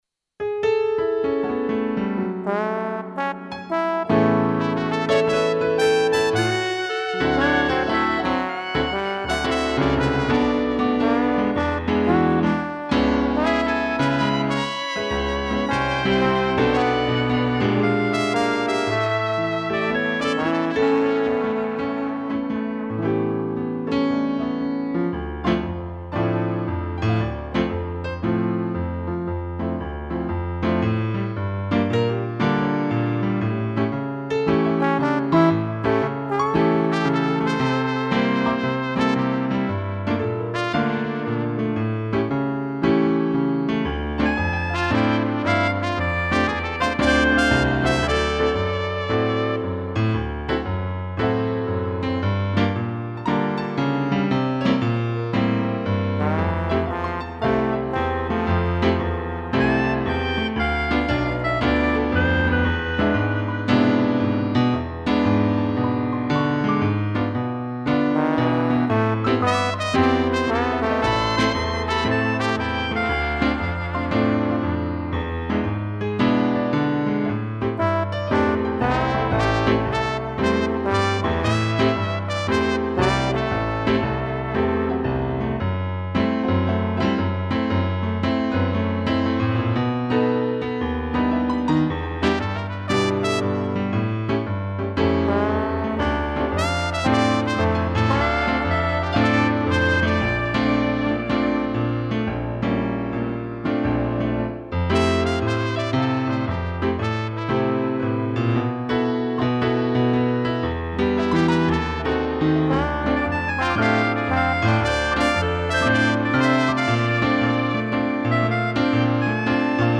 2 pianos, trombone, trompete e clarinete
(instrumental)